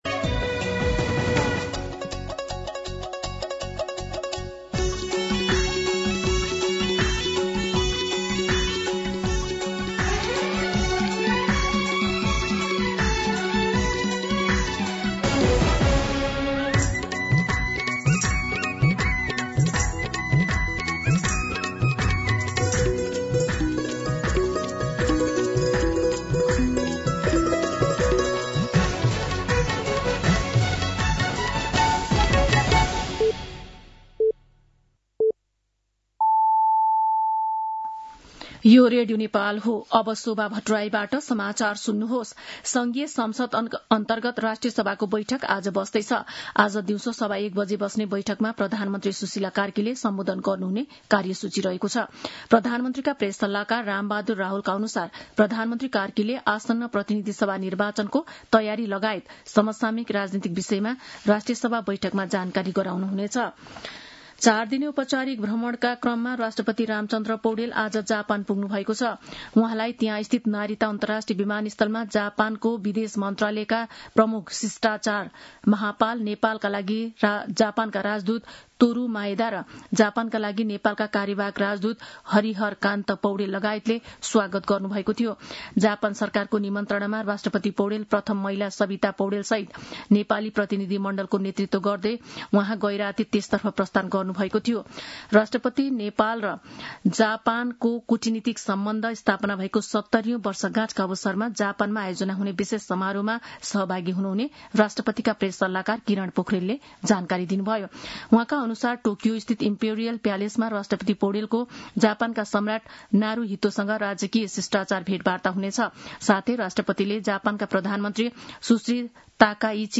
मध्यान्ह १२ बजेको नेपाली समाचार : १९ माघ , २०८२
12pm-News-19.mp3